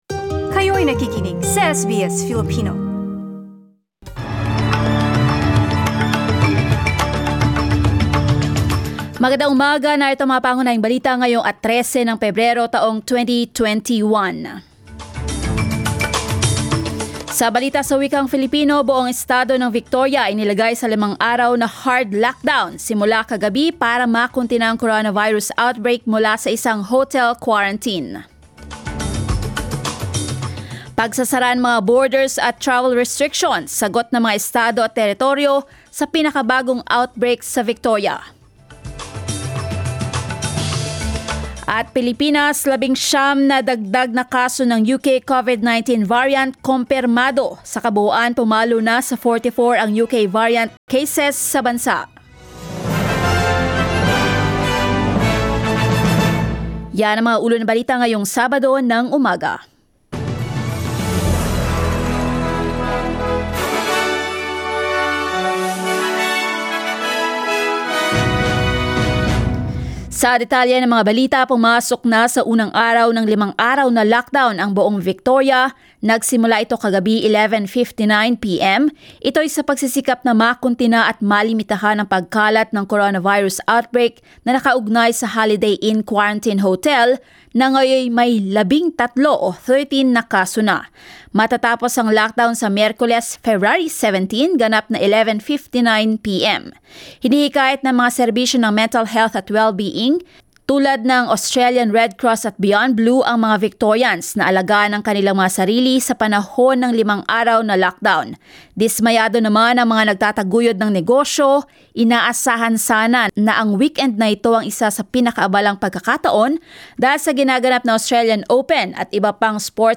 SBS News in Filipino, Saturday 13 February